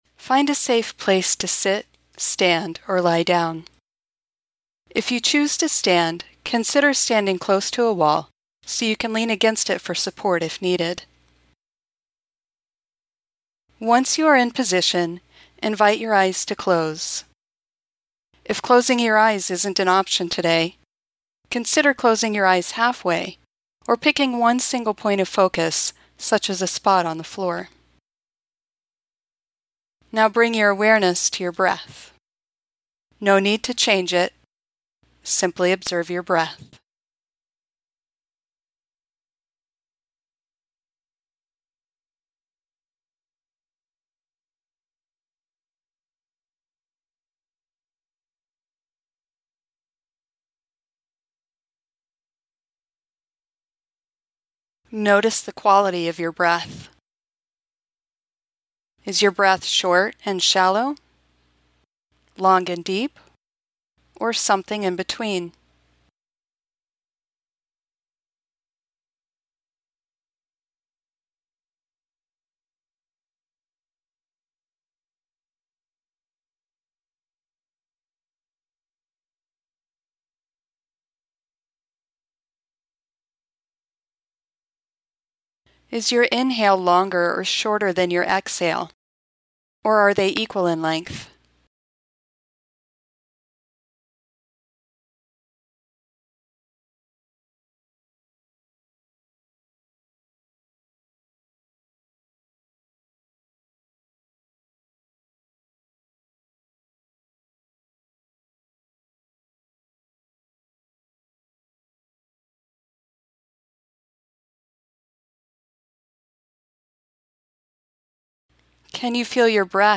my-body-is-a-battleground-my-body-is-sacred-space-meditation.mp3